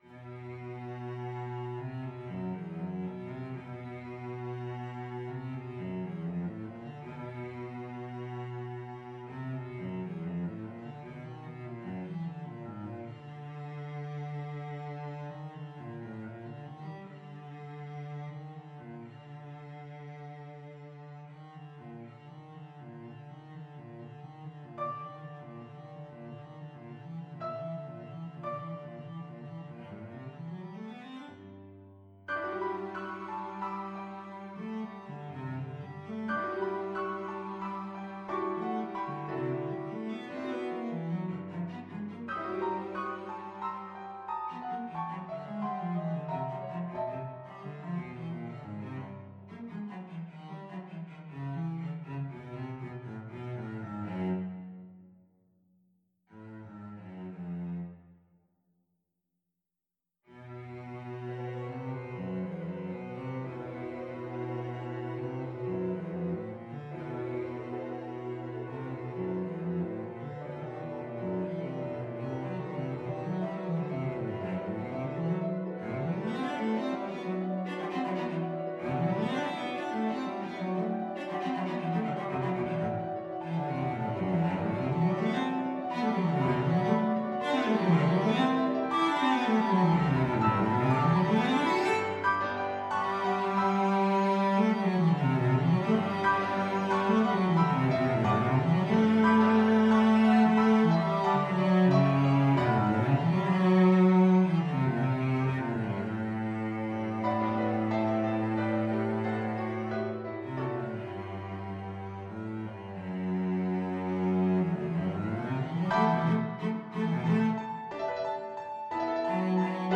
CelloPiano
Andante =60
3/4 (View more 3/4 Music)
Cello  (View more Advanced Cello Music)
Classical (View more Classical Cello Music)